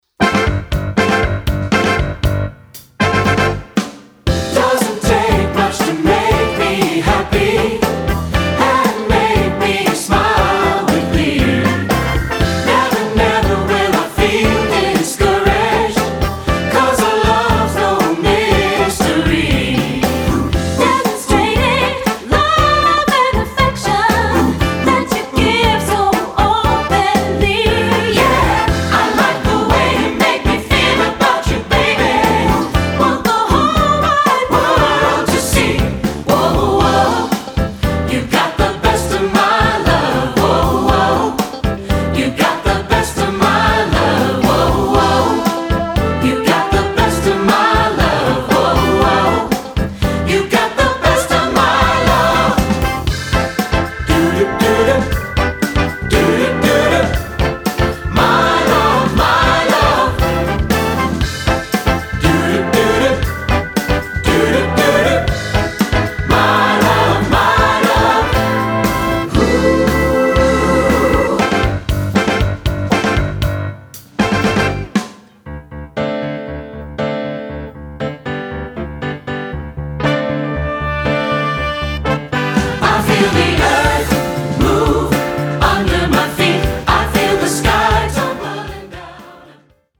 Choral 70s-80s-90s Pop